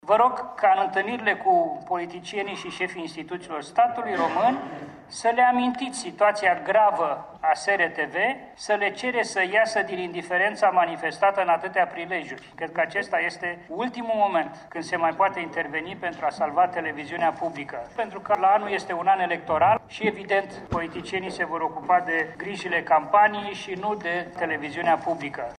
Stelian Tănase a citit în faţa comisiei şi un paragraf din această scrisoare, dar a cerut ca restul conţinutului să rămână secret presei: